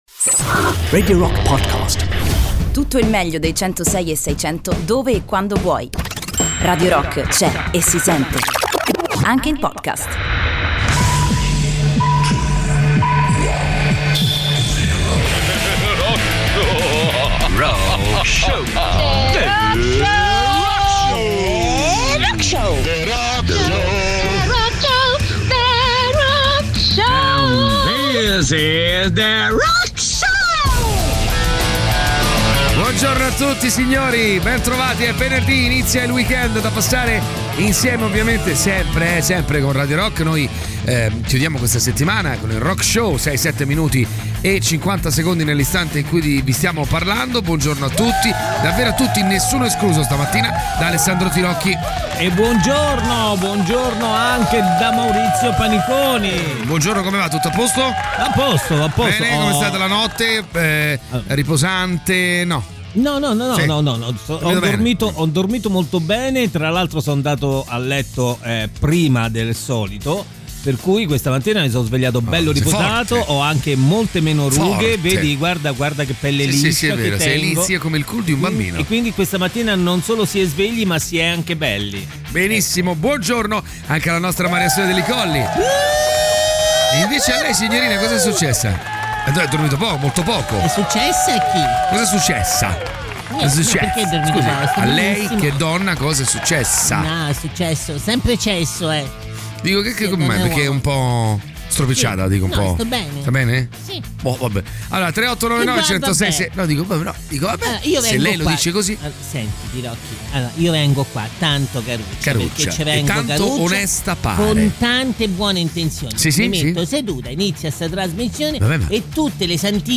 in diretta dalle 06.00 alle 08.00 dal Lunedì al Venerdì sui 106.6 di Radio Rock.